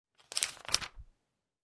На этой странице собраны звуки журналов: шелест страниц, перелистывание, легкие постукивания обложки.